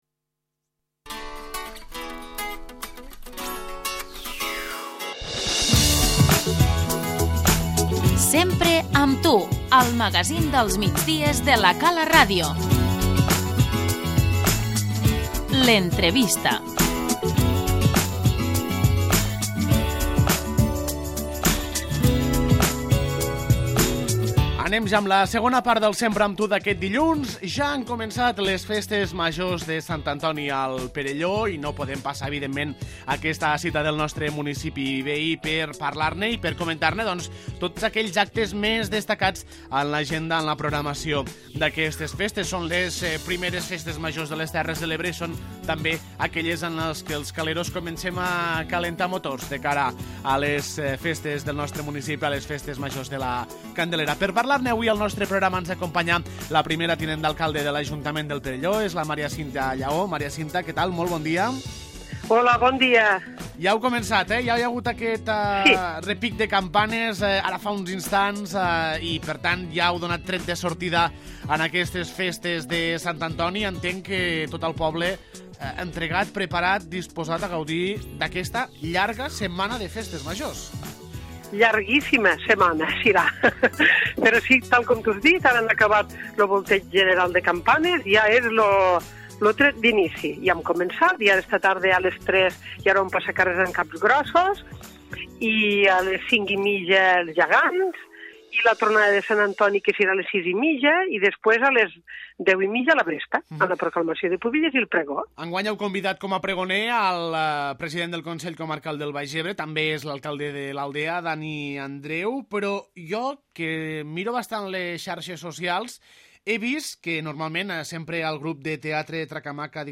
L'entrevista - Maria Cinta Llaó, primera tinent d'alcalde del Perelló
El Perelló inicia aquest dilluns les seves Festes Majors de Sant Antoni. Ens explica tots els detalls del programa d'actes la primera tinent d'alcalde, Maria Cinta Llaó.